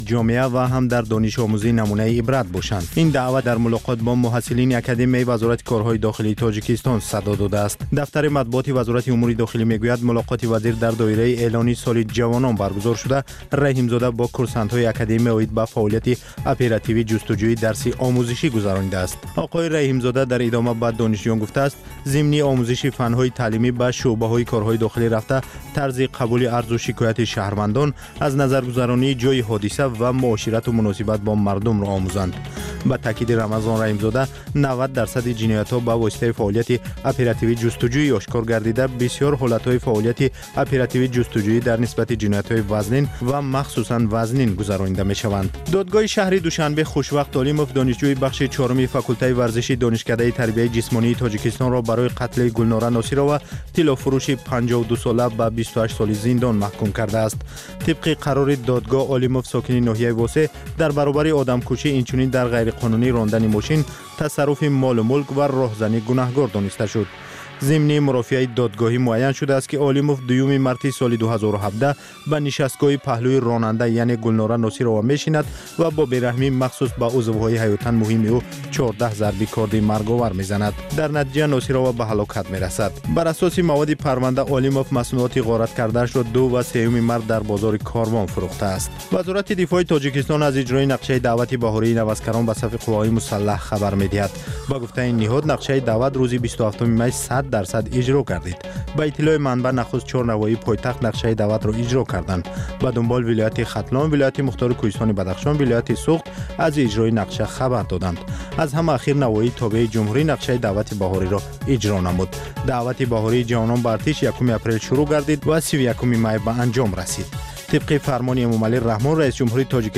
Гуфтори вижаи Радиои Озодӣ аз ҳаёти ҷавонони Тоҷикистон ва хориҷ аз он